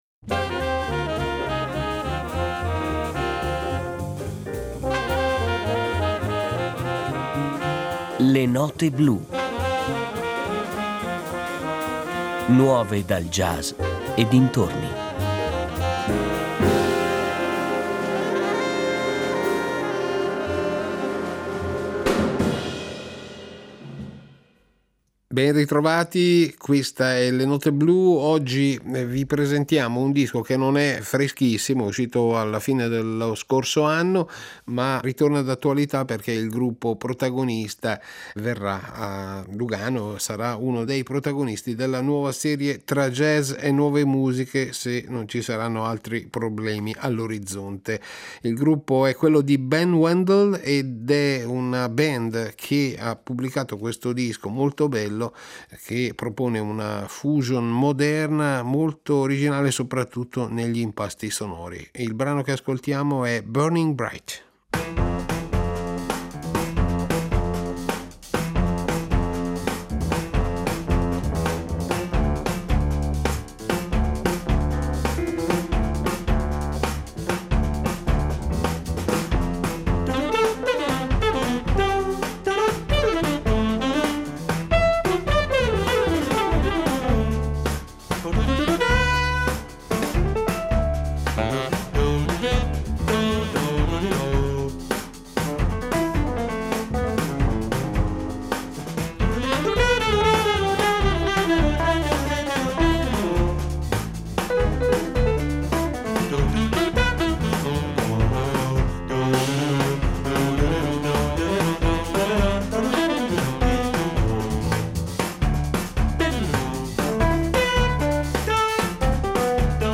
jazz
basso
batteria